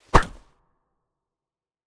KS_Gorey Slash_1.wav